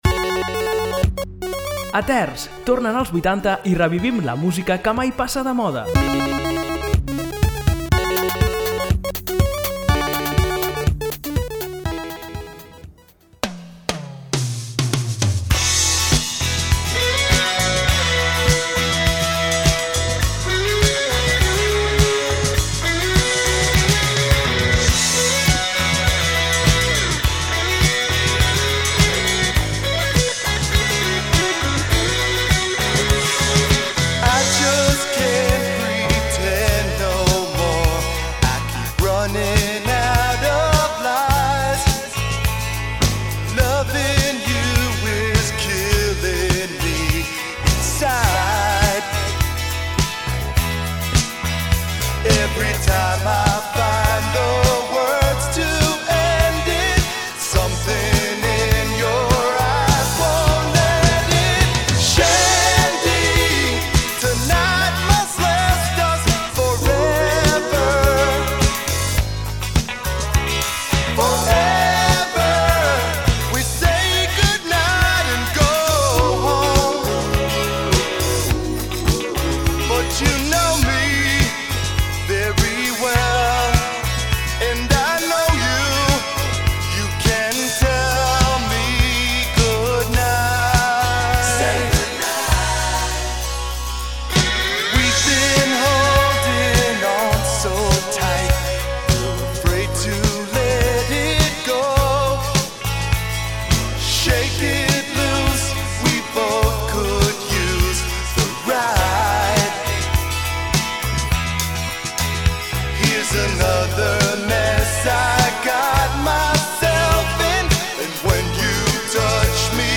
Tornen els 80 i revivim la millor música que mai passa de moda